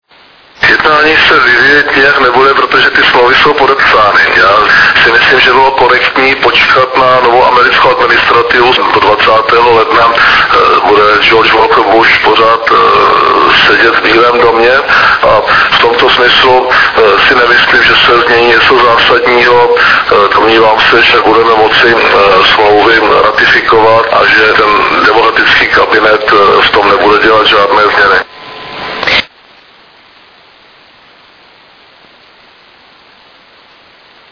Předseda vlády včera pro média uvedl, že změna americké administrativy neovlivní výstavbu radarové základny u nás.